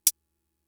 closedhats.WAV